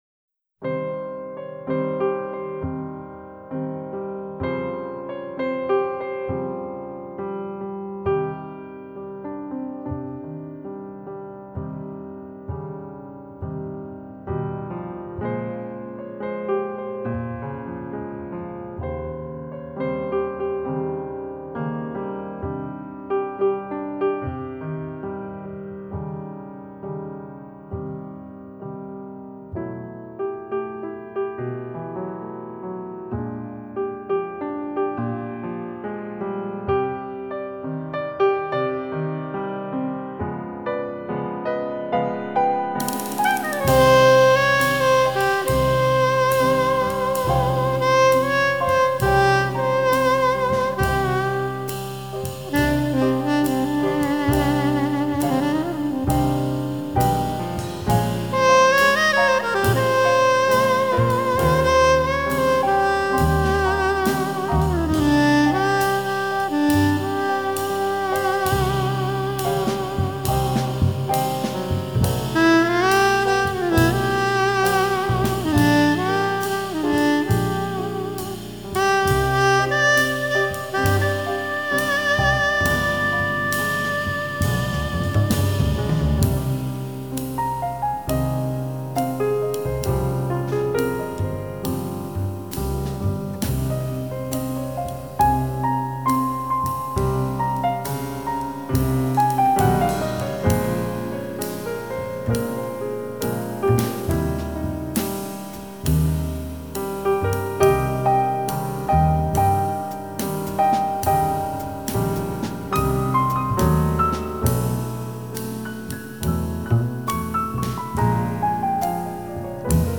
violin playing soars